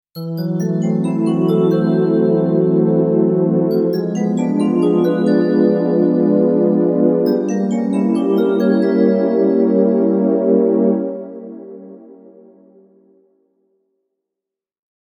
Tense Fantasy Transition Sound Effect
Description: Tense fantasy transition sound effect. A magical, whimsical, and dreamy transition sound effect creates a sense of wonder and enchantment.
Tense-fantasy-transition-sound-effect.mp3